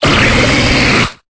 Cri de Muplodocus dans Pokémon Épée et Bouclier.